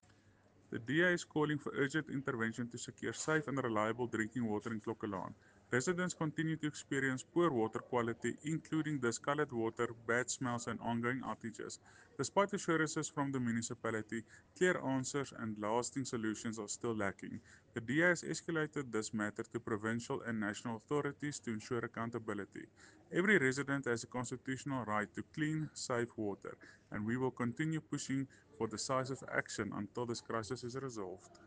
Afrikaans soundbites by Cllr Jose Coetzee and